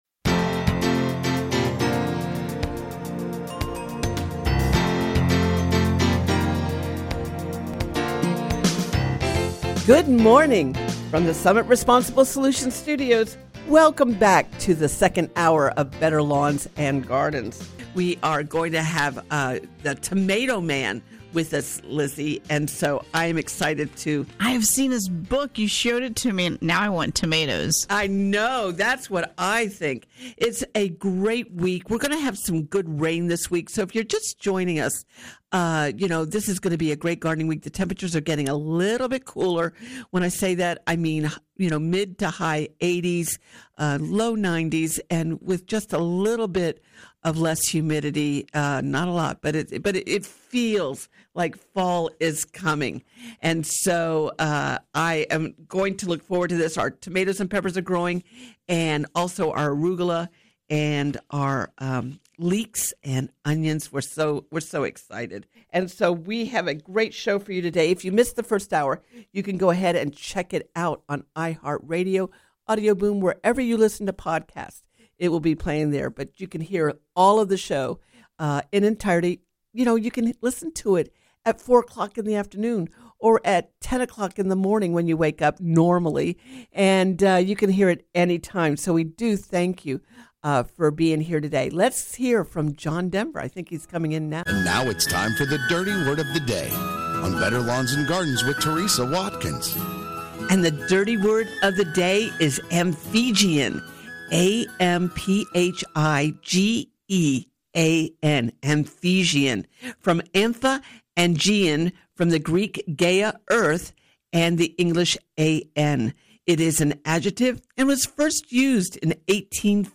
Better Lawns and Gardens Hour 2 – Coming to you from Summit Responsible Solutions Studios
Listen every Saturdays from 7am - 9am EST on WFLA- Orlando.